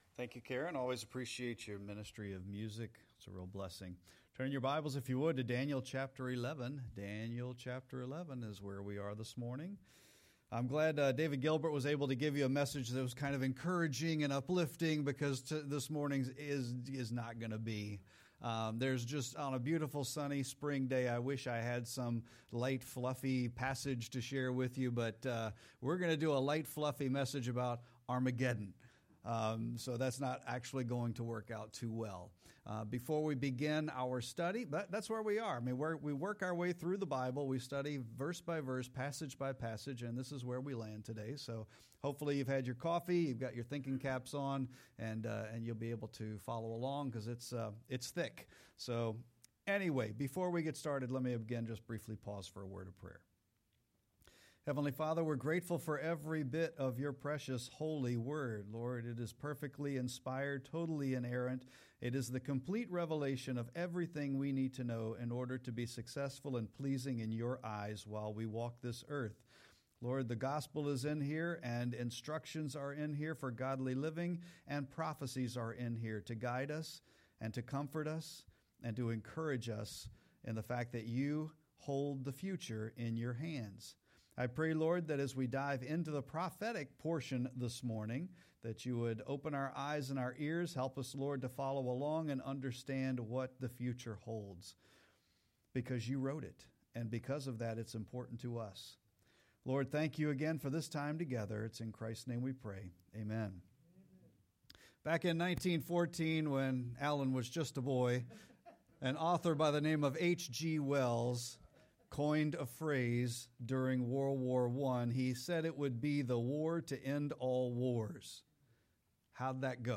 Sermon-3-23-25.mp3